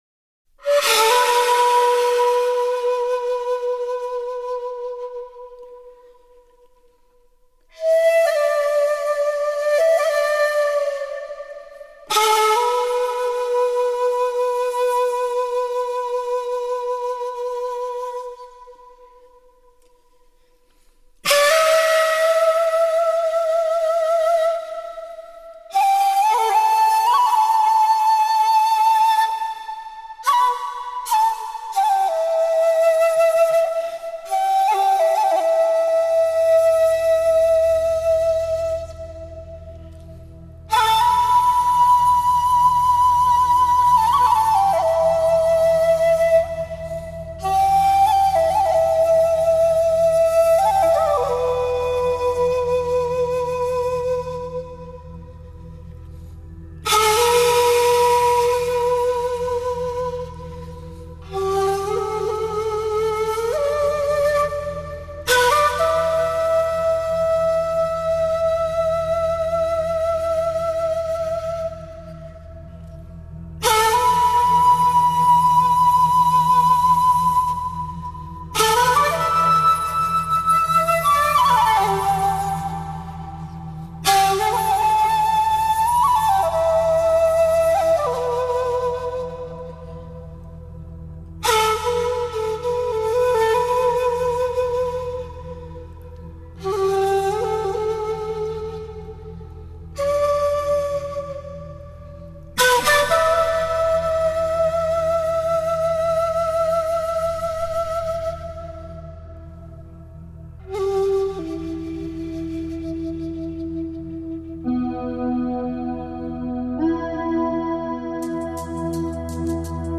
笛音虽委婉，听来却悲悯，有羽翼无痕那种隐约的苍凉。
竹笛，在本辑中有较多的运用。
一抑一扬都饱含感情和温度。